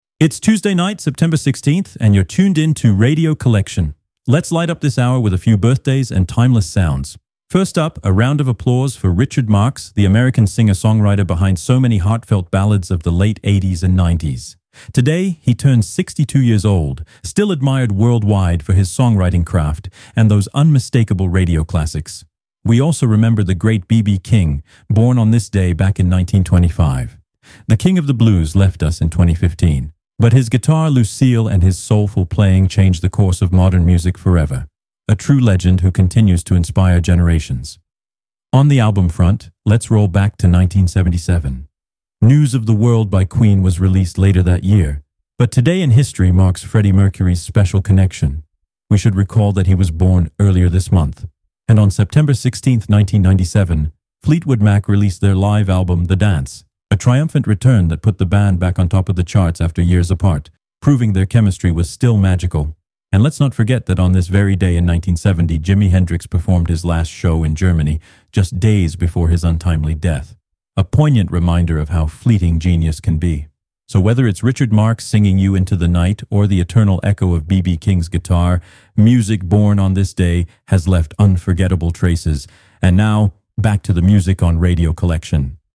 You're listening to the Pop Rock column on Radio Collection, the free, ad-free web radio station that broadcasts the greatest classics and new releases in Hi-Fi quality.